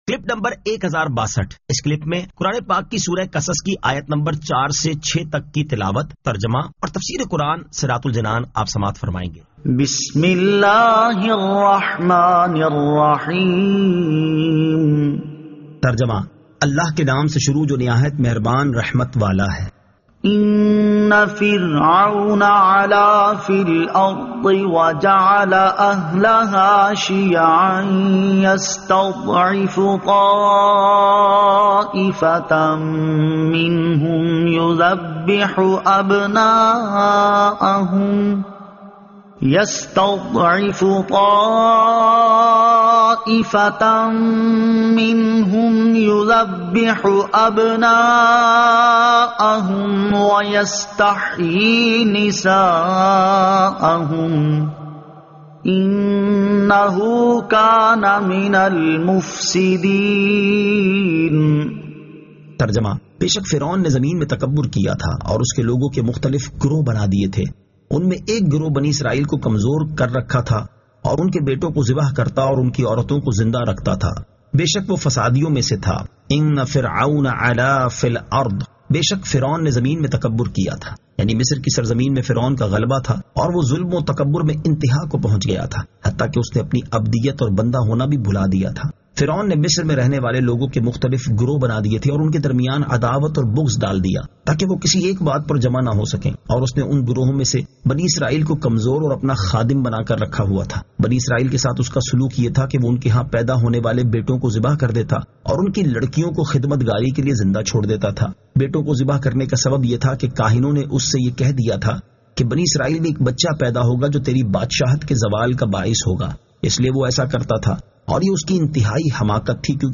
Surah Al-Qasas 04 To 06 Tilawat , Tarjama , Tafseer